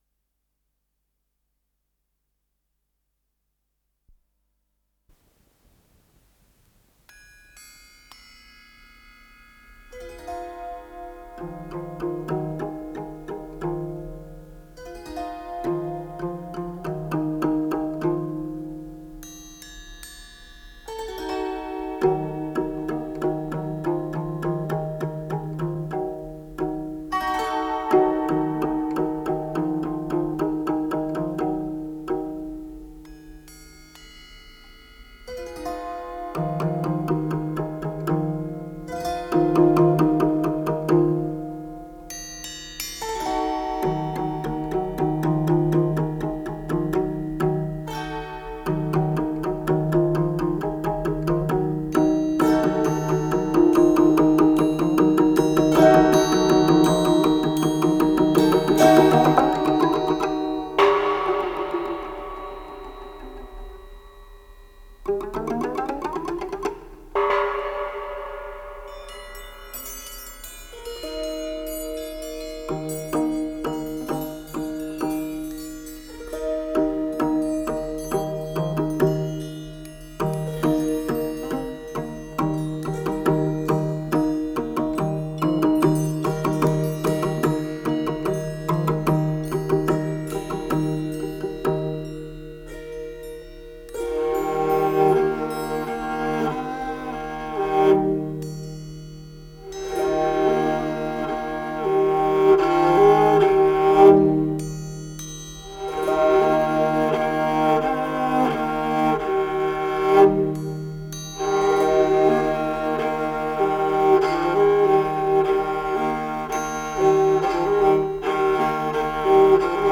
с профессиональной магнитной ленты
ПодзаголовокДве финские народные мелодии
ИсполнителиМартти Покеля - струнная лира
кантеле, колкола, пила
ВариантДубль моно